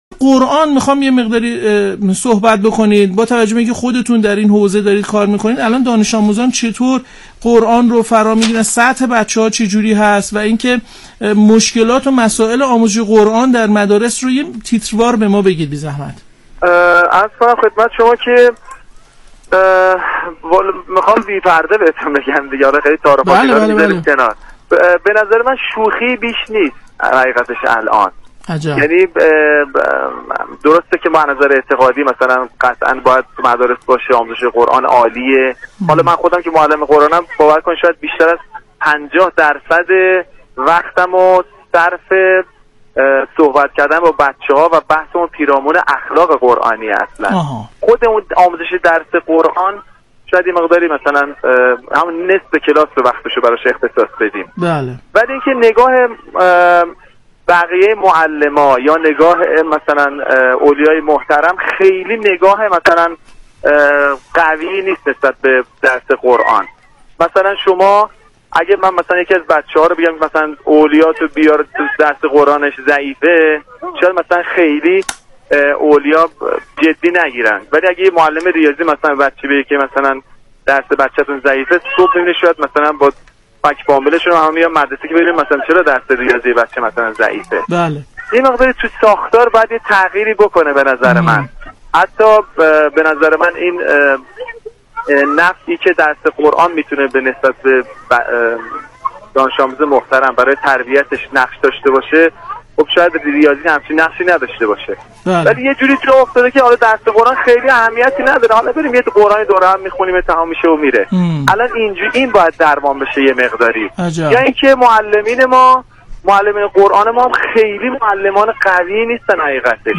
در برنامه زنده افق رادیو قرآن، با اشاره به تبلیغات ضعیف درباره رشته علوم و معارف اسلامی و عدم آشنایی مشاورین پایه نهم با این رشته، بر ضرورت رعایت متوازن‌سازی انتخاب تمامی رشته‌ها در پایه نهم و لزوم ورود معلمان قرآن به یادگیری فن بیان و علوم روز تأکید شد.